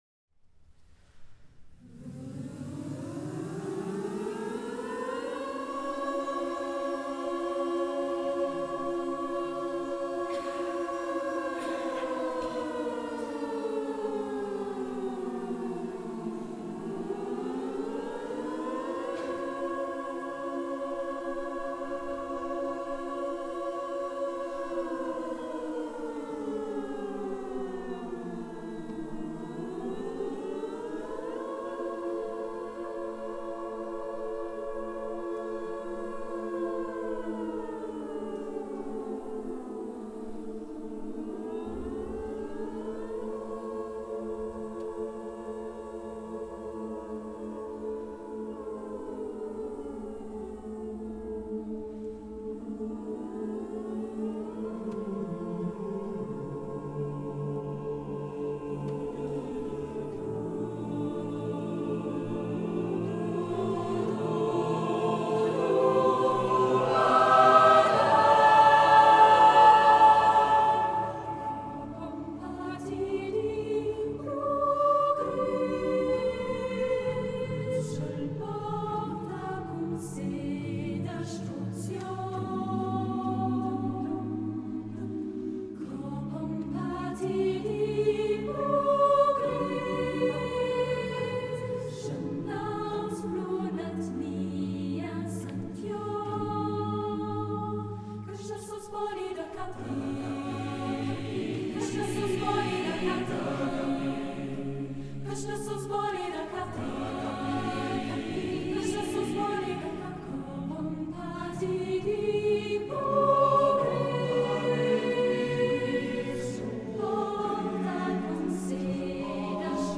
Tëuta su ai 27.04.2025 tla dlieja de Urtijëi